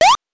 8 bits Elements
jump_4.wav